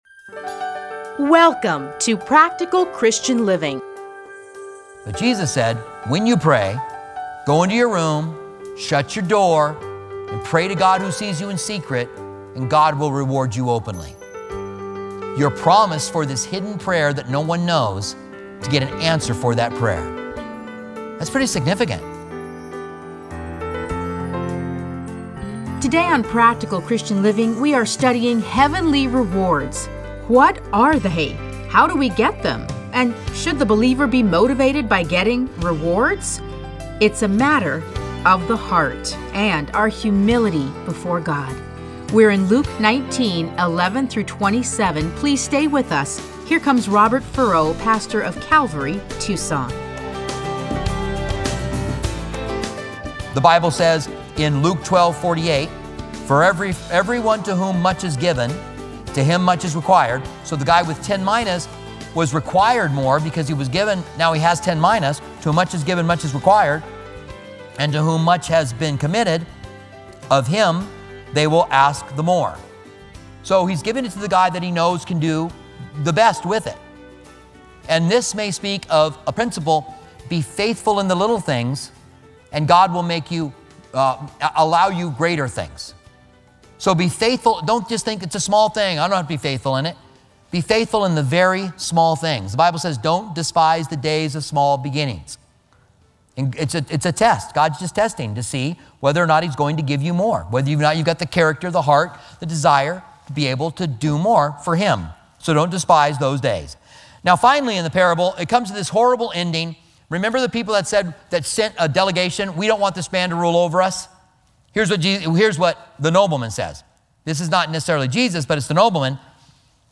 Listen to a teaching from Luke 19:11-27.